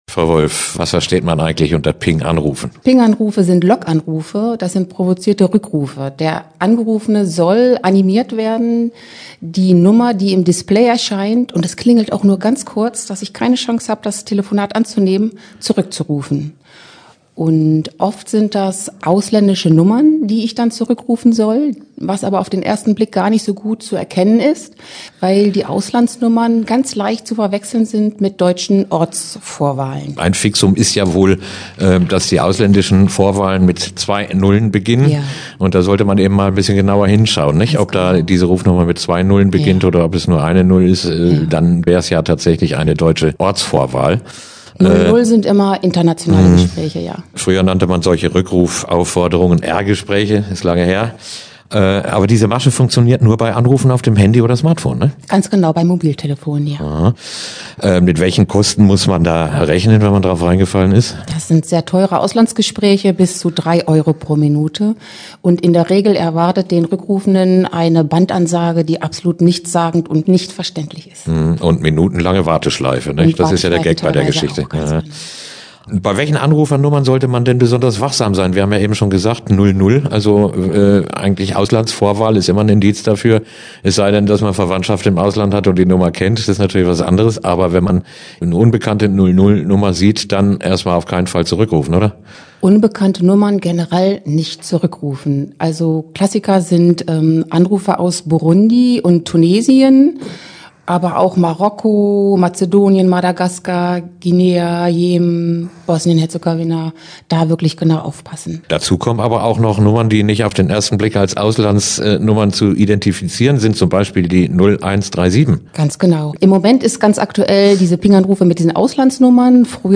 Interview Verbraucher 25-04 Ping-Anrufe
Interview-Verbraucher-25-04-Ping-Anrufe.mp3